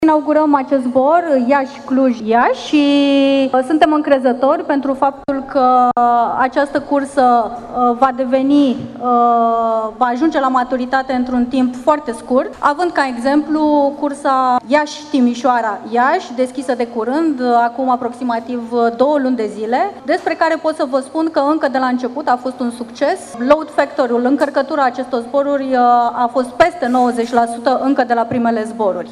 Lansarea noului zbor intern a fost marcată printr-o conferință de presă în zona de plecări a terminalului T3, la care au participat reprezentanți ai companiei Tarom, conducerea Aeroportului Iași și a Consiliului Județean Iași, precum și reprezentanți ai mediului de IT și de afaceri local. Invitații au ținut discursuri, iar la final au tăiat tortul tematic.